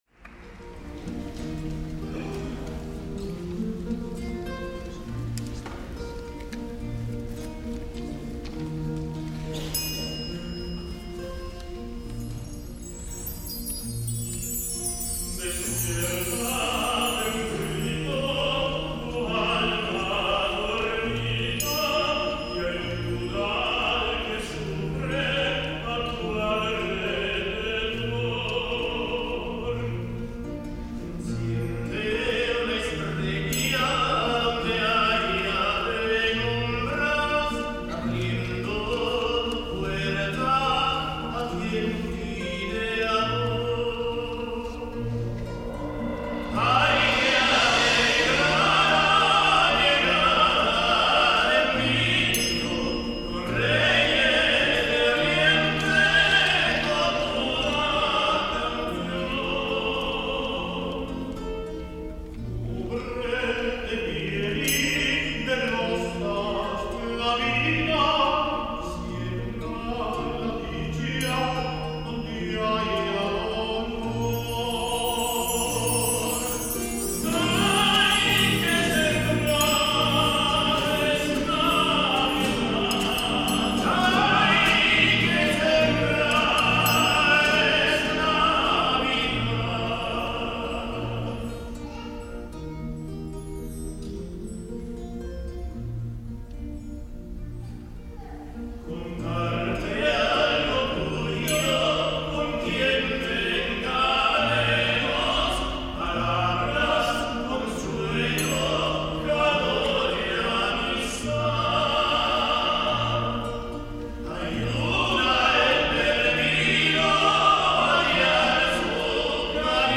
S. Gaudenzio church choir Gambolo' (PV) Italy
Dal Concerto di Natale 2004